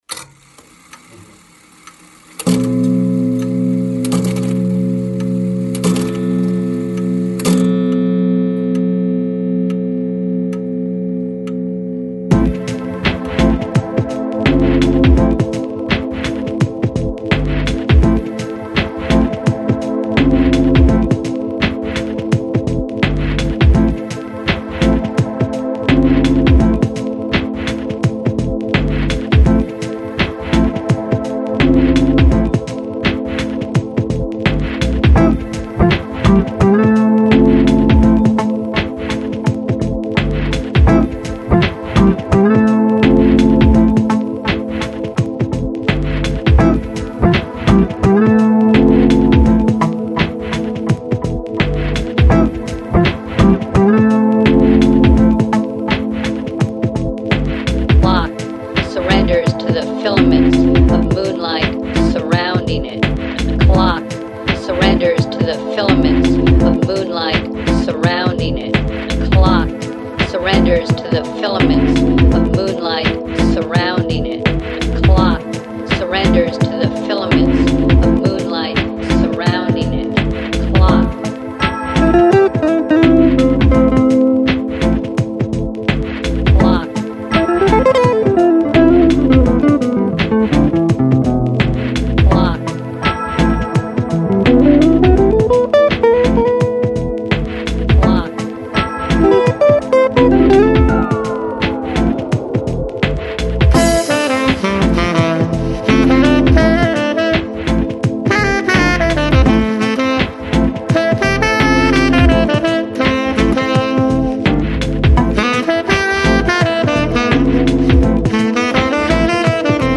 Жанр: Electronic, Lounge, Chill Out, Downtempo, Trip Hop